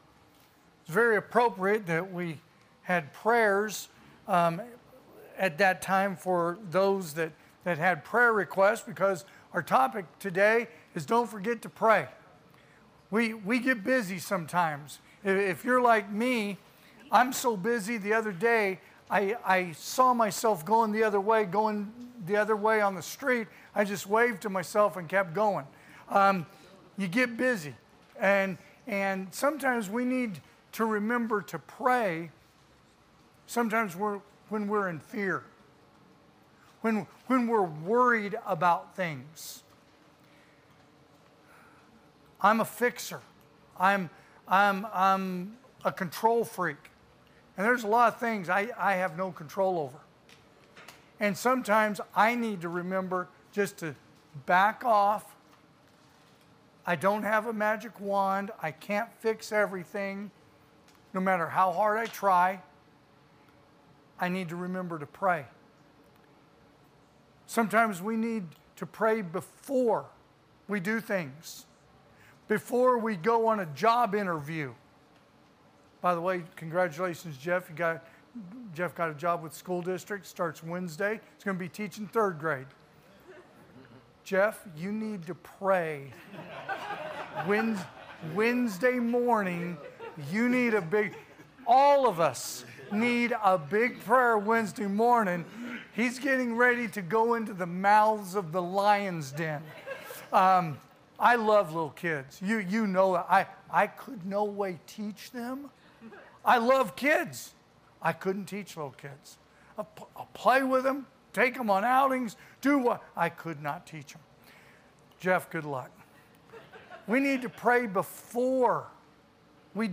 AM Worship
Sermons